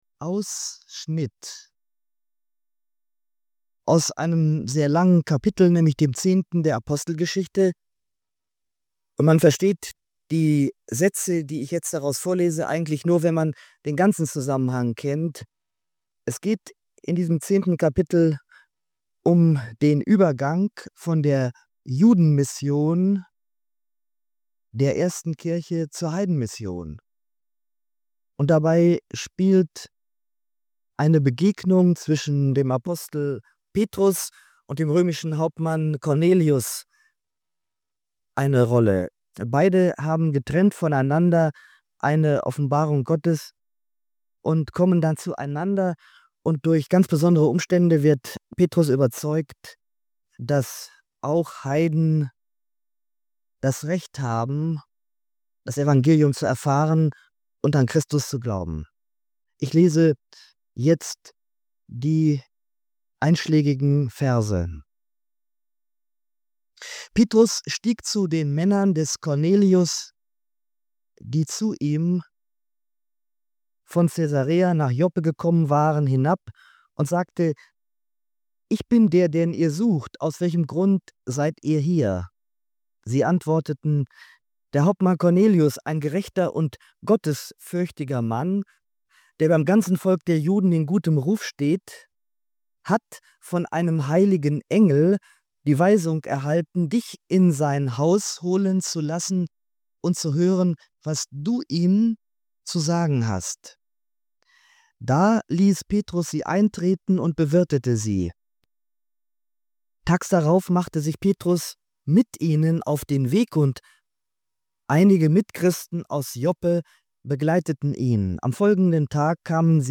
Diese Predigt legt das 10. Kapitel der Apostelgeschichte aus und stellt die Begegnung zwischen Petrus und dem römischen Hauptmann Cornelius in den Mittelpunkt.